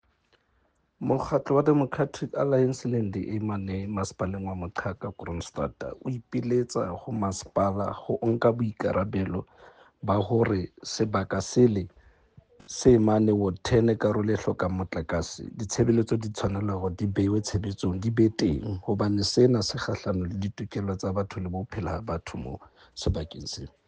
Sesotho soundbite by Cllr Dennis Khasudi.